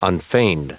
Prononciation du mot unfeigned en anglais (fichier audio)
Prononciation du mot : unfeigned